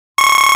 AJ_Warning_1.ogg